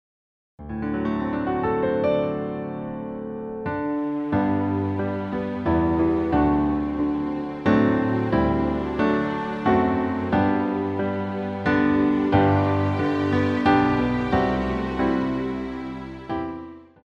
• Tonart: A Dur, G Dur (weitere auf Anfrage)
• Art: Klavier Streicher
• Einleitung kurz + 2 Verse (z.b englisch & deutsch)
• Das Instrumental beinhaltet NICHT die Leadstimme
Klavier / Streicher